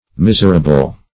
Miserable \Mis"er*a*ble\, n.